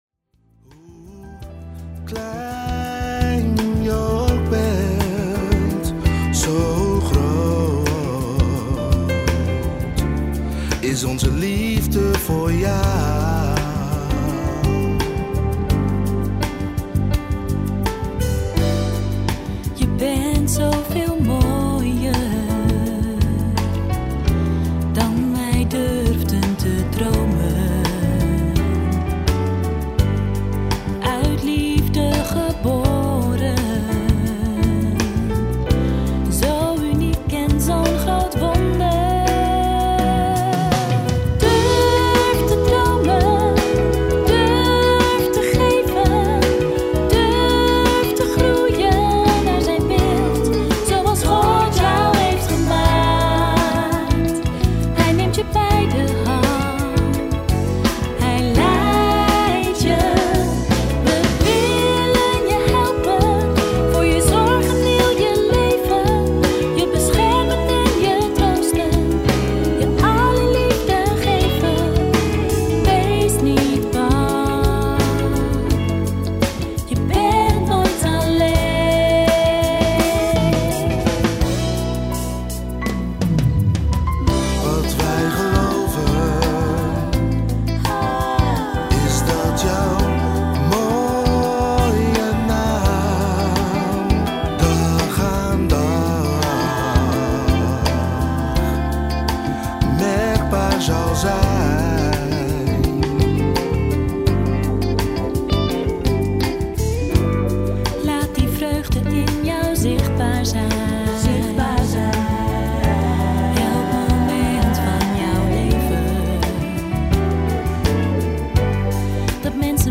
Gospel Pop, Praise & Worship